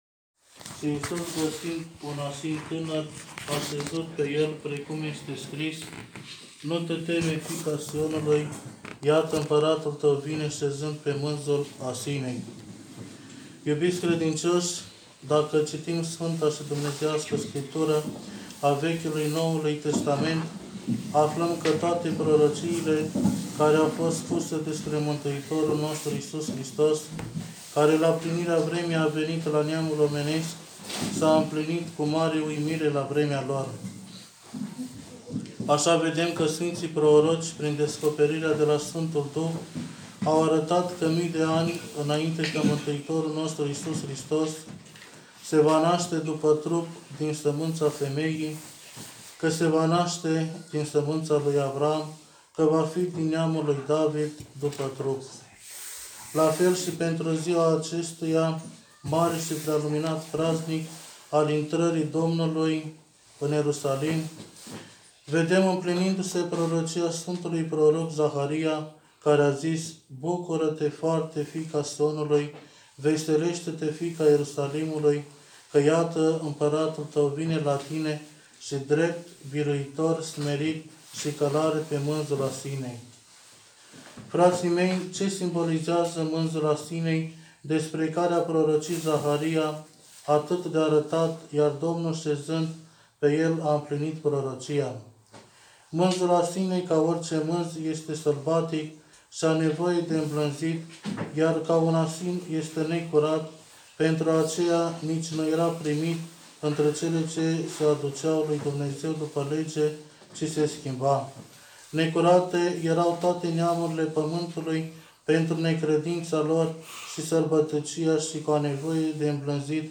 predica poate fi descărcată în format audio mp3 de aici: